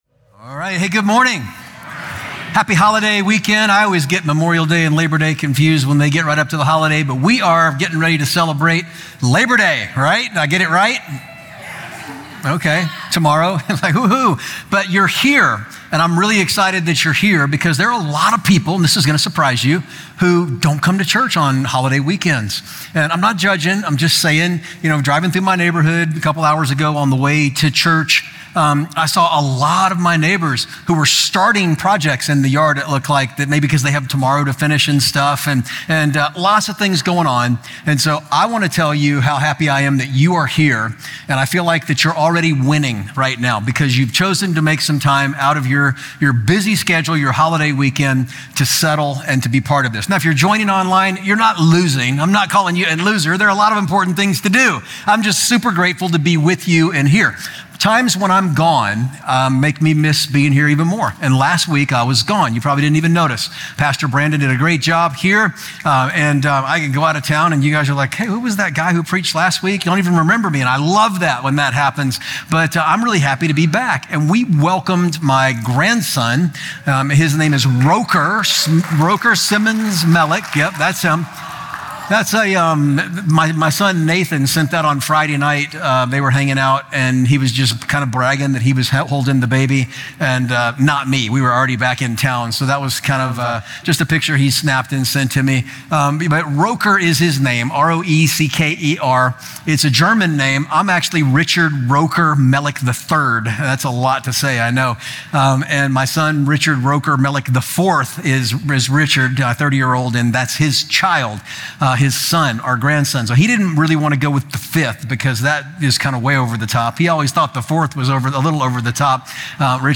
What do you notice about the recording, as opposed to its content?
Includes Scripture from: Exodus 20:3-18 Full Service -Long Version